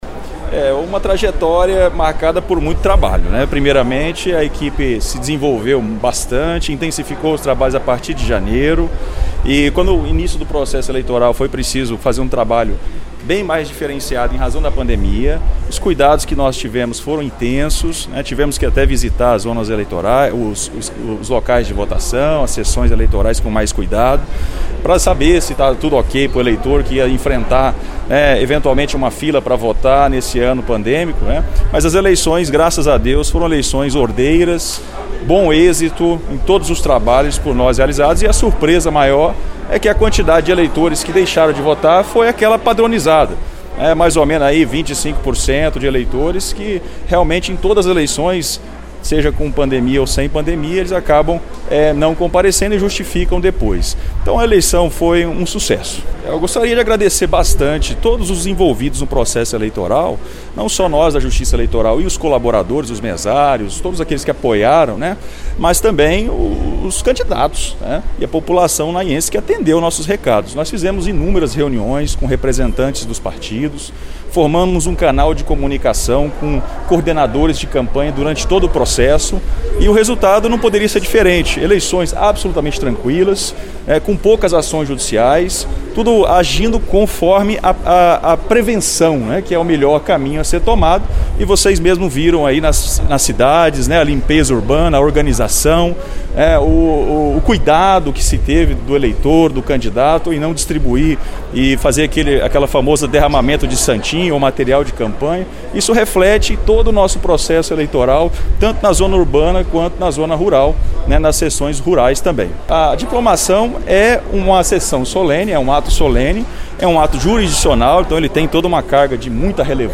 A reportagem da Rádio Veredas ouviu alguns dos personagens deste evento que consolidou a democracia nos dois municípios.